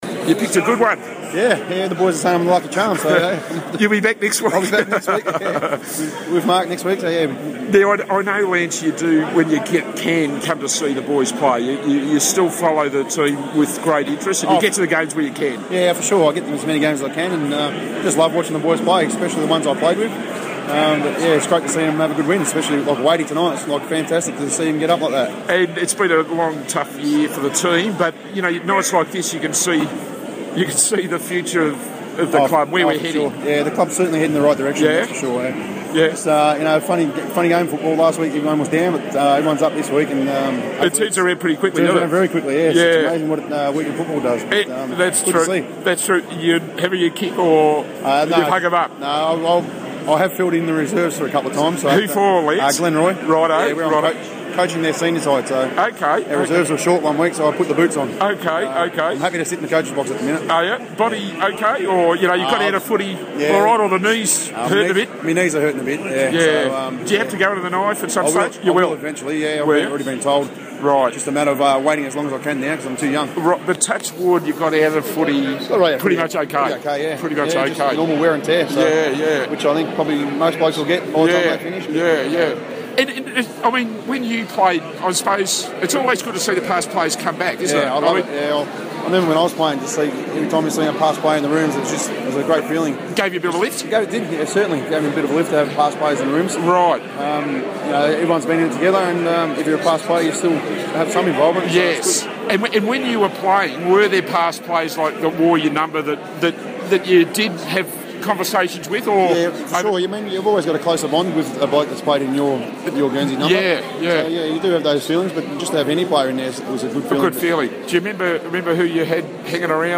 Former skipper Lance Whitnall, speaking from the Carlton rooms on Friday night, says he believes the Club is heading in the right direction.